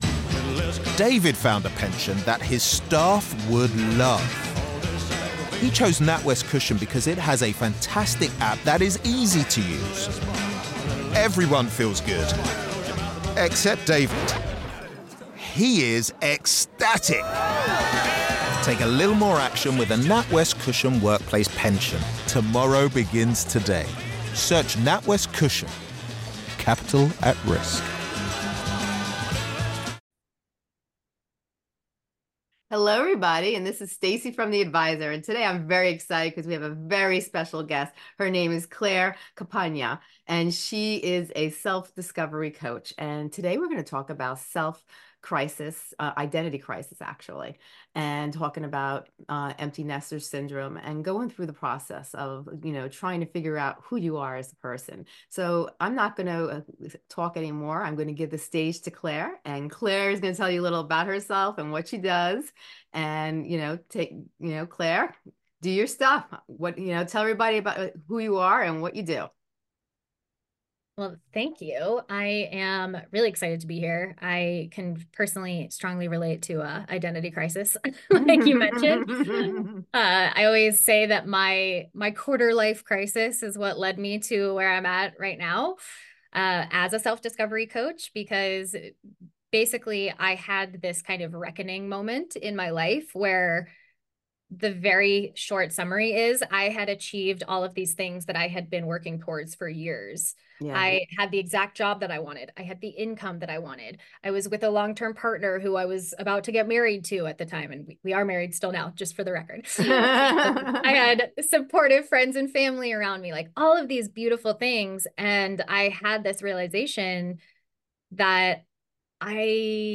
Whether facing an identity crisis or preparing for an empty nest, this enlightening conversation will provide guidance, inspiration, and practical tips to help you embrace change and discover new possibilities.